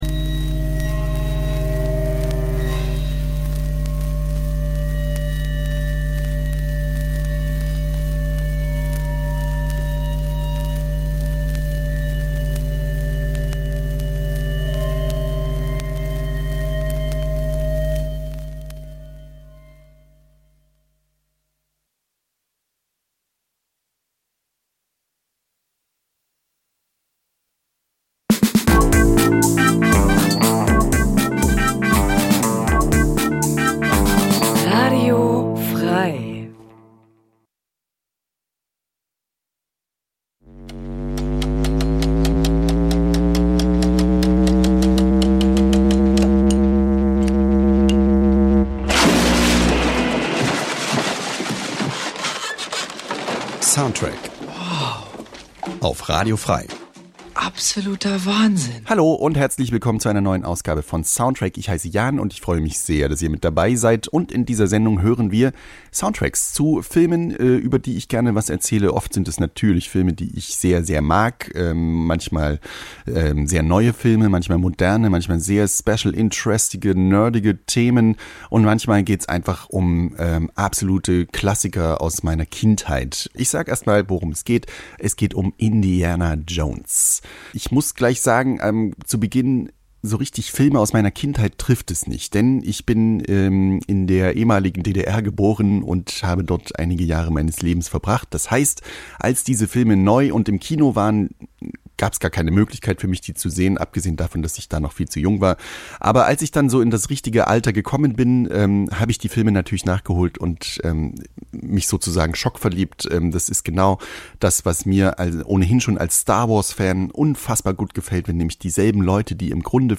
Wöchentlich präsentieren wir ausgesuchte Filmmusik.